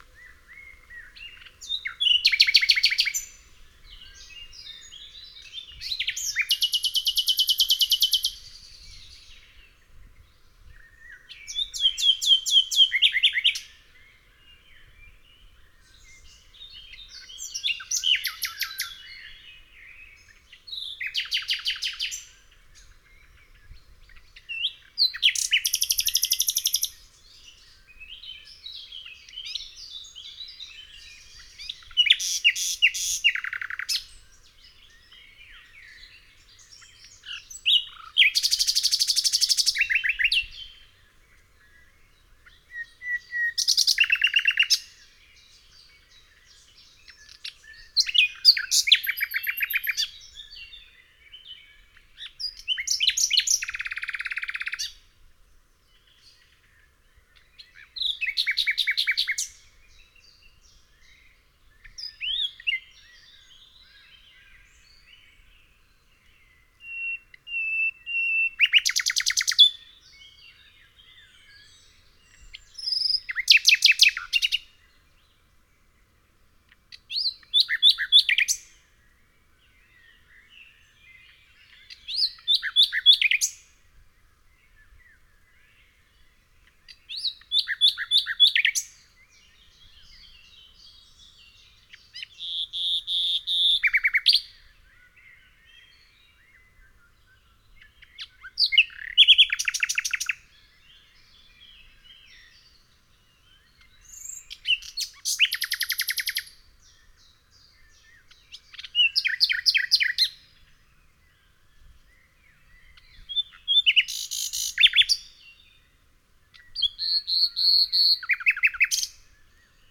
Nightingale song